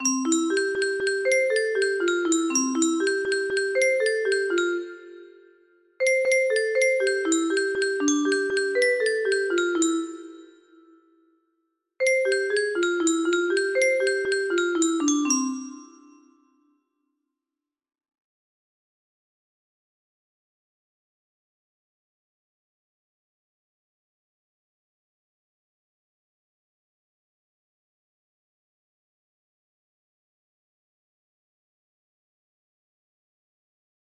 Tapani 2 music box melody